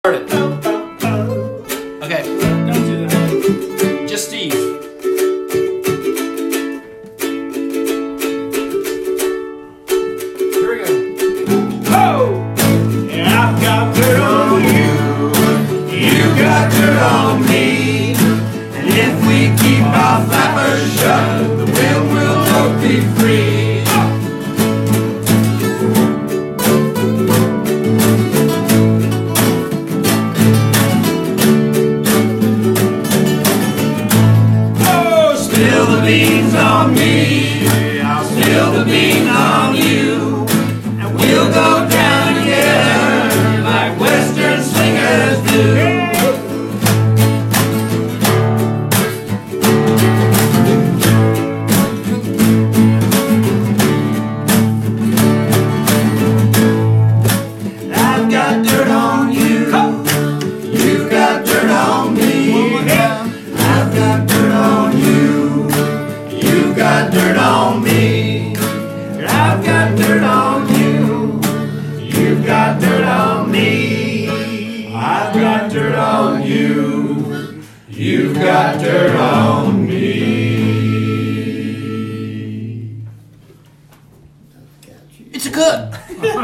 Huffbunny demo